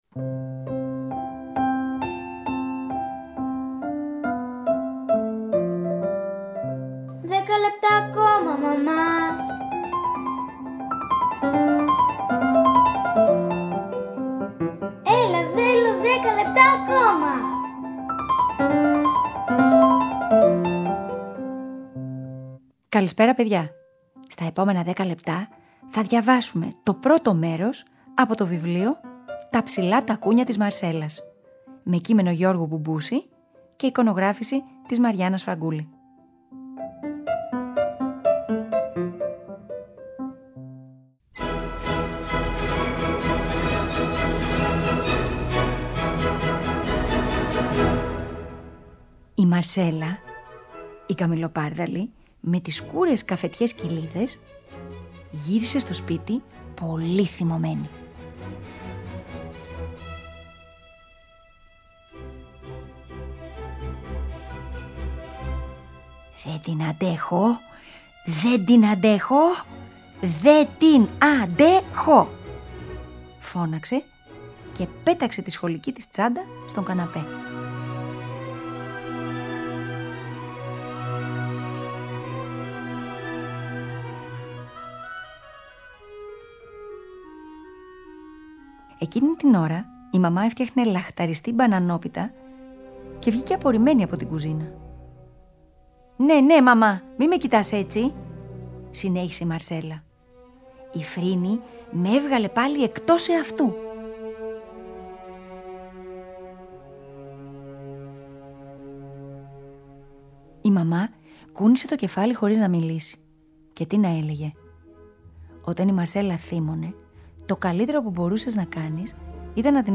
Σήμερα θα διαβάσουμε ένα βιβλίο για την φιλία και για την προσπάθεια που χρειάζεται να καταβάλουμε να διατηρήσουμε την ισορροπία στις σχέσεις μας.